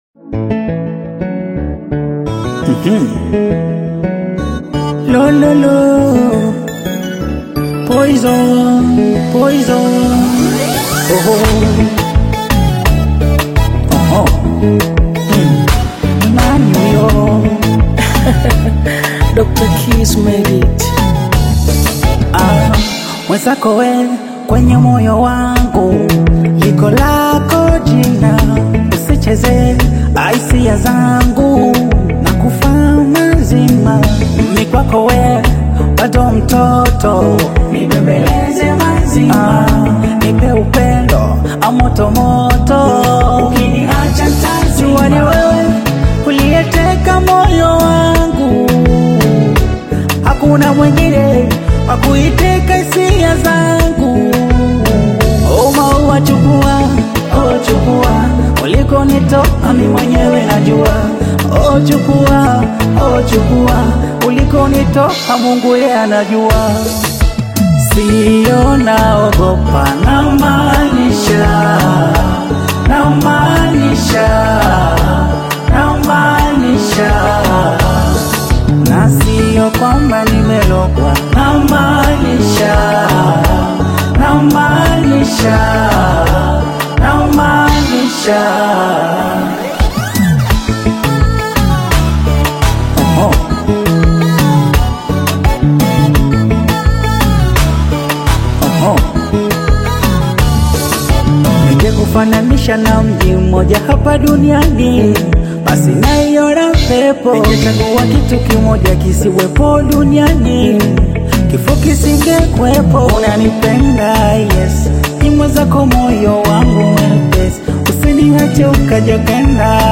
Bongo Flava / Afrobeat single